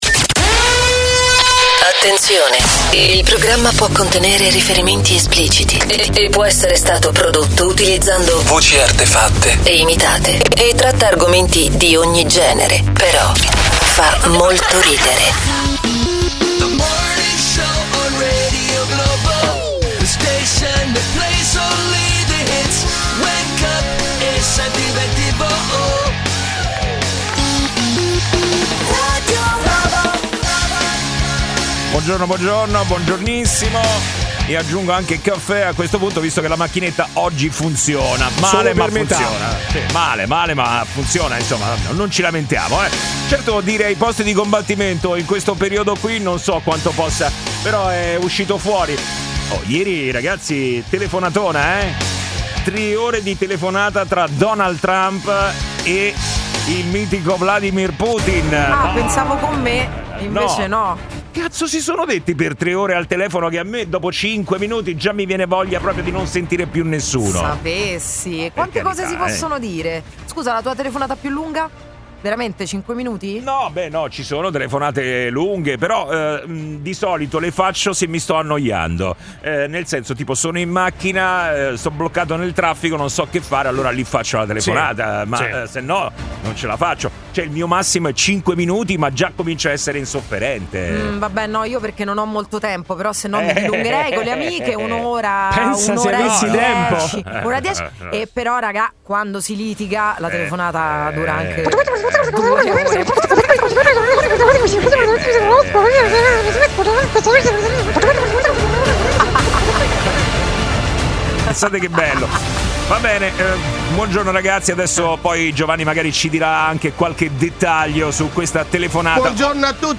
Umorismo, attualità, aggiornamenti sul traffico in tempo reale e l'immancabile contributo degli ascoltatori di Radio Globo, protagonisti con telefonate in diretta e note vocali da Whatsapp.